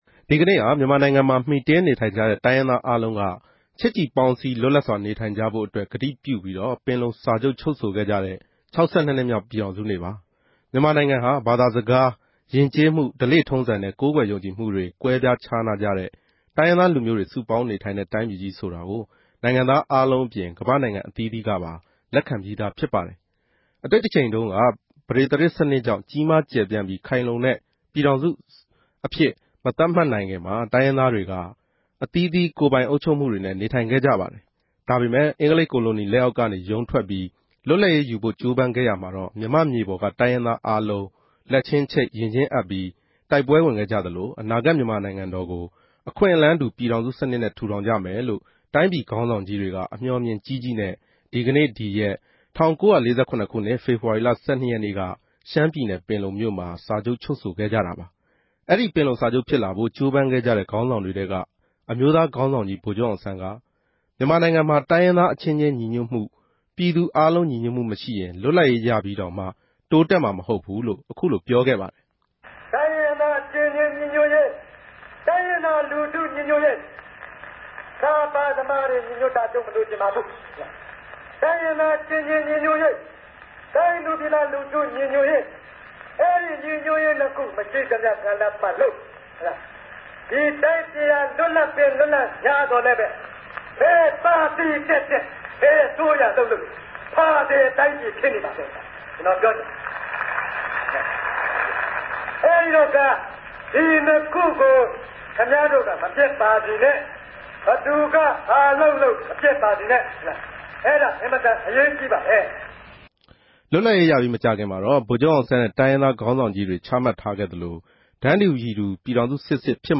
ဒီကနေႛကဵရောက်တဲ့ ၆၂ ိံြစ်ေူမာက် ူပည်ထောင်စုနေႛမြာ ပင်လုံစာခဵြပ်ူဖစ်လာဖိုႛ ဋ္ဌကိြးပမ်းခဲ့ုကတဲ့ ခေၝင်းဆောင်တေထြဲက အမဵိြးသားခေၝင်းဆောင် ဗိုလ်ခဵြပ်အောင်ဆန်း ေူပာခဲ့ဘူးတဲ့ ညီႌြတ်ရေး မိန်ႛခြန်းကို ူပန်လည်တင်ူပမြာ ူဖစ်သလို၊ ူပည်တြင်း္ဘငိမ်းခဵမ်းရေး၊ စစ်မြန်တဲ့ ူပည်ထောင်စိုံိုင်ငံ တည်ဆောက်ရေး၊ တိုင်းရင်းသားအားလုံး တန်းတူ အခြင့်အရေး ရရြိရေးနဲႛ၊ ဒီမိုကရေစီရေးတေအြတြက် ဋ္ဌကိြးပမ်းနေတဲ့ ဒေၞအောင်ဆန်းစုုကည် ေူပာဆိုခဲ့ဘူး ပင်လုံစိတ်ဓာတ်နဲႛ ပတ်သက်တဲ့ စကားတခဵိြႚကိုလည်း တင်ူပထားပၝတယ်။